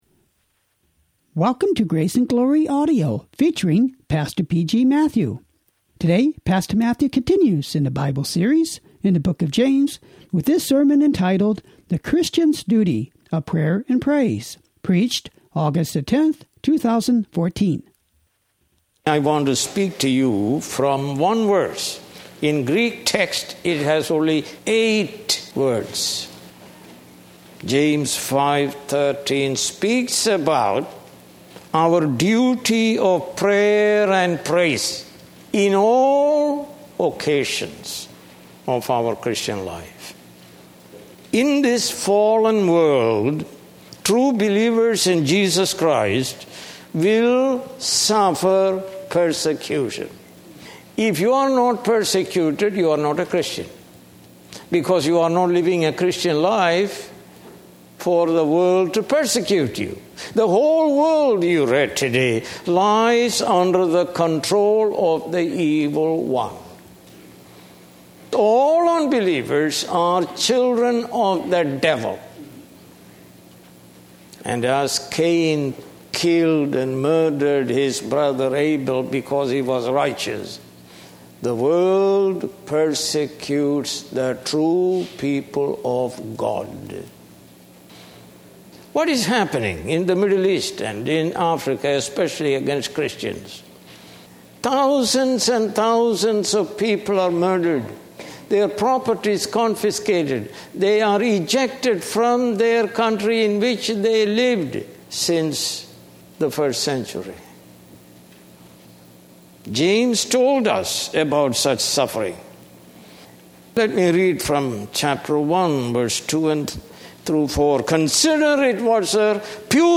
More Sermons From the book of James